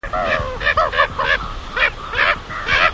Gavina capblanca (Larus genei)